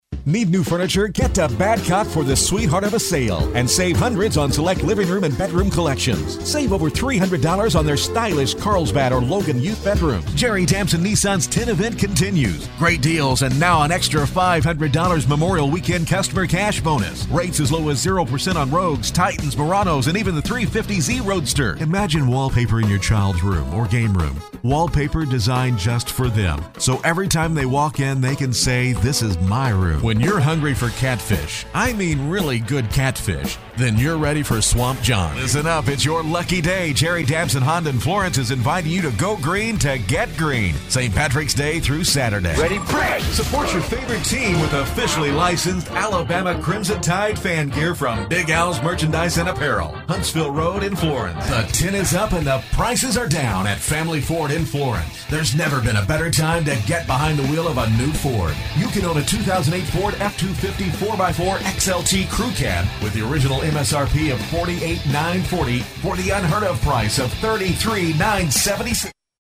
Male
English (North American)
Adult (30-50), Older Sound (50+)
Confident, clear, smooth, professional are attributes often associated to my work.
Warm, dry, intimate, mature, friendly, conversational and comfortable to upbeat, energetic, authoritative, witty, wise, quirky, generation x are the types I can provide.
Main Demo